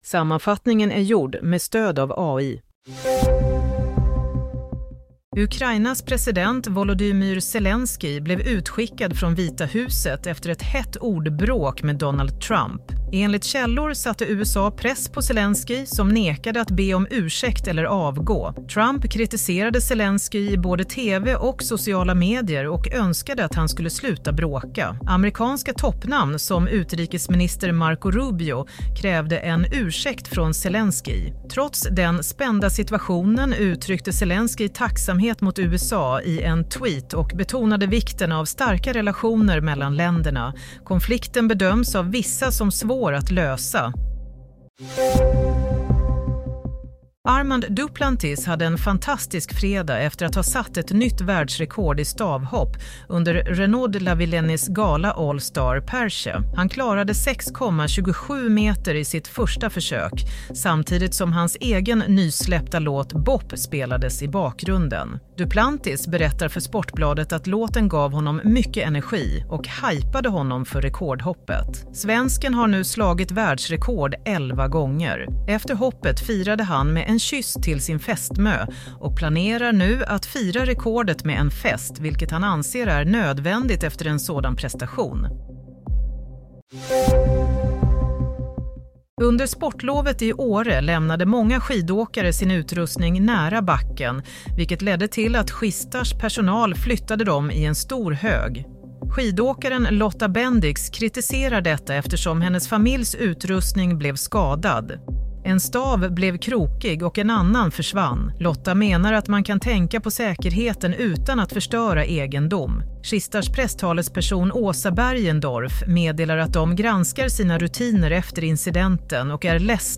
Nyhetssammanfattning - 1 mars 07:00